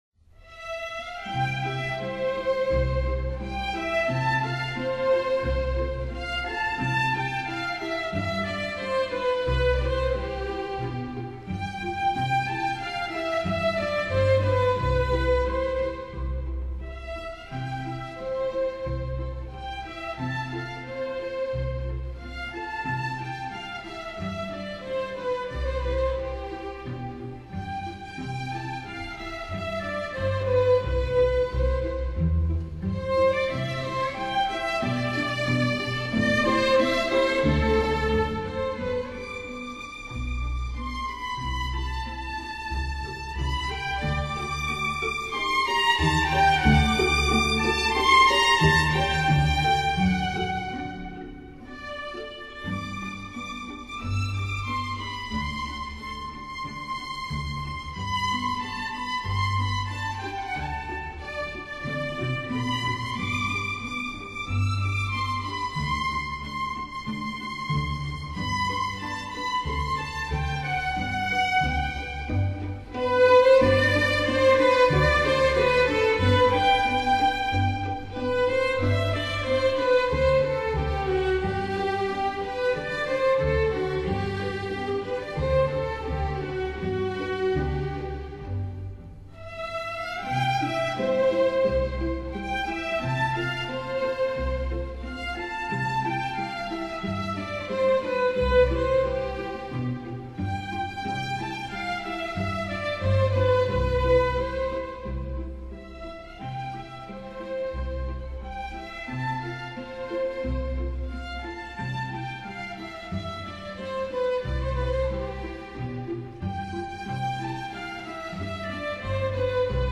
旋律优美，精致无比的乐曲改善心灵状态